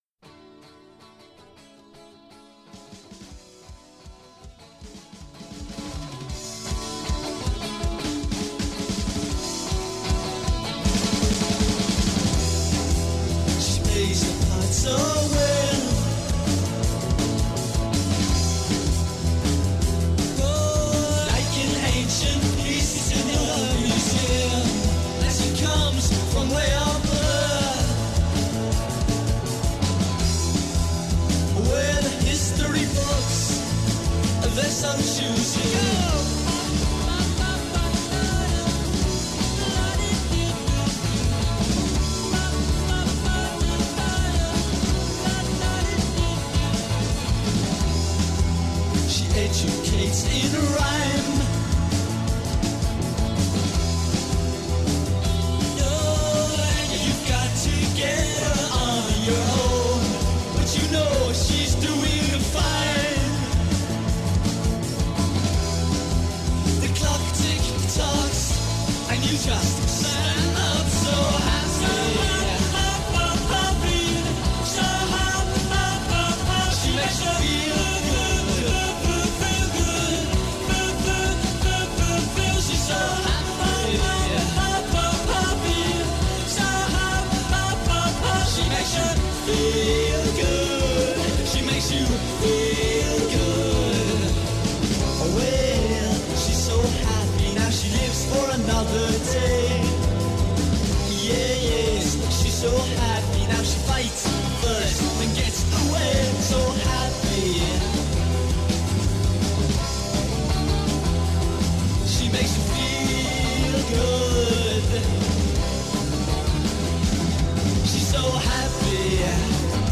One more stop in this indiepop world tour.
drums
bass
vocals and guitar